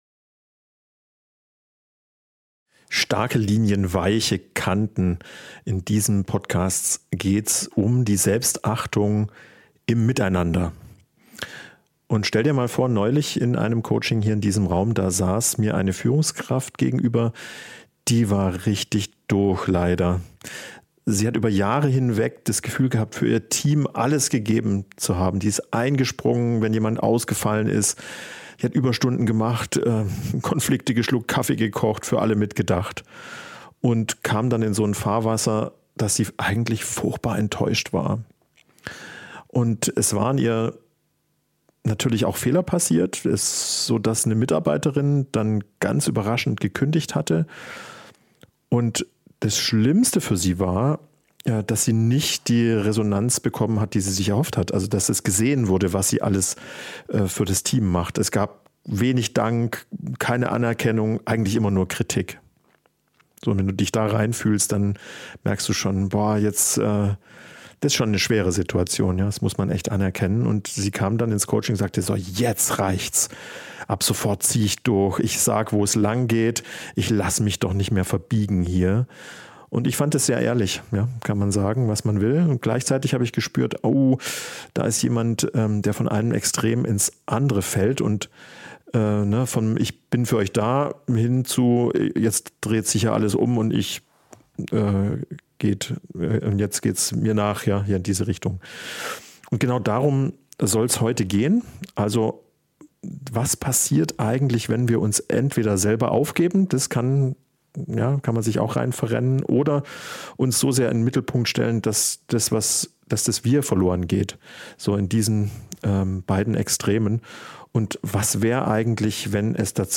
In dieser Solofolge erzähle ich von einer Führungskraft, die jahrelang alles für ihr Team gegeben hat – und am Ende enttäuscht, erschöpft und verbittert war.